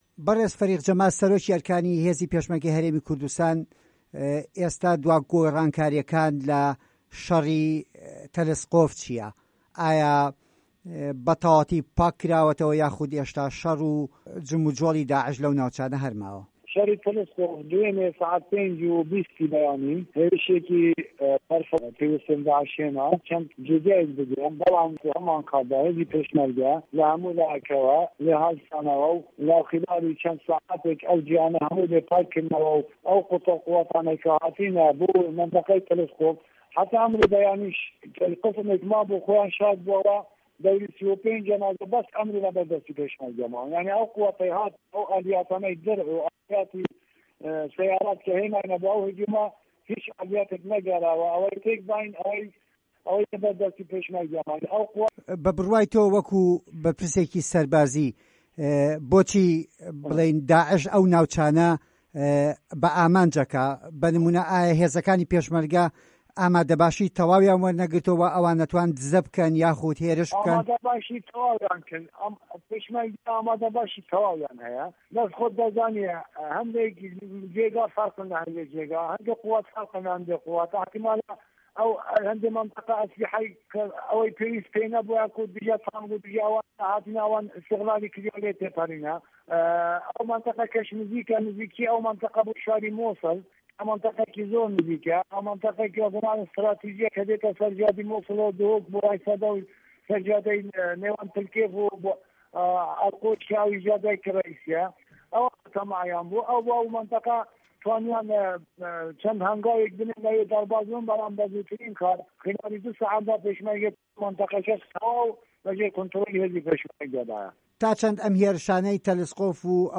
ده‌قی و تووێژه‌که‌ی به‌شی کوردی له‌گه‌ڵ سه‌رۆکی ئه‌رکانی هێزی پێشمه‌رگه‌ی هه‌رێمی کوردستان، له‌م فایله‌ ده‌نگیەدایە.
وتووێژ لەگەڵ فه‌ریق جه‌مال محەمەد عومەر